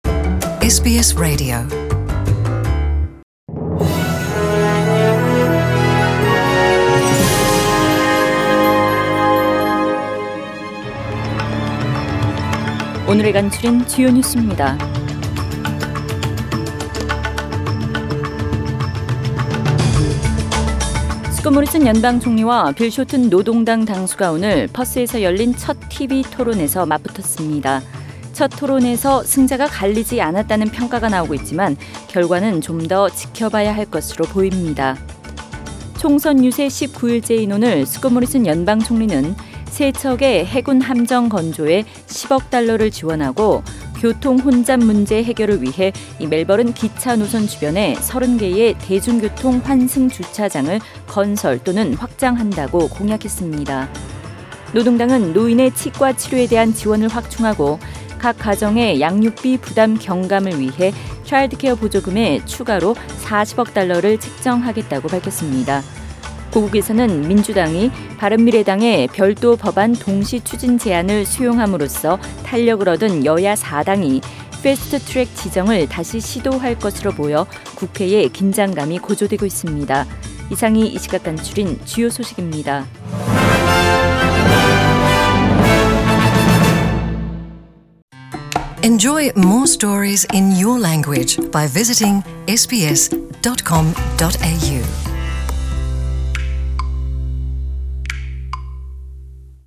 SBS 한국어 뉴스 간추린 주요 소식 – 4월 29일 월요일